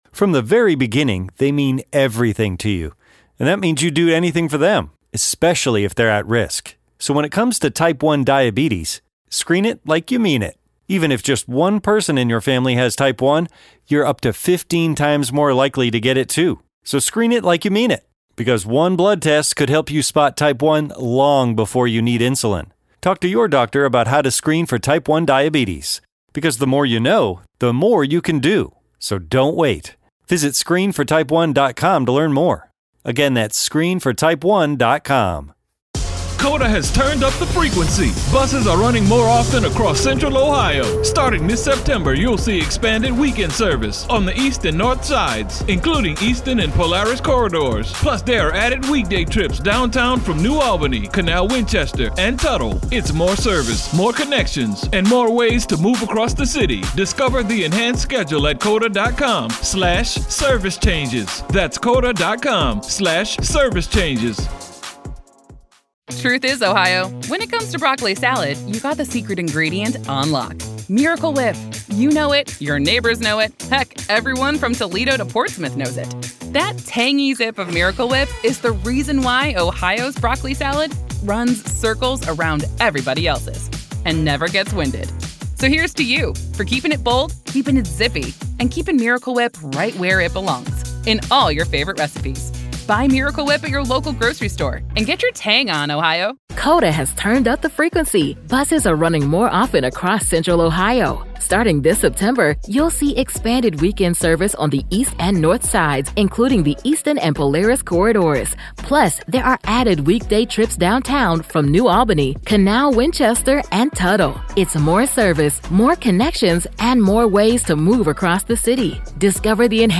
LISTEN: The Trial Of Lori Vallow Daybell Day 6 Part 4 | Raw Courtroom Audio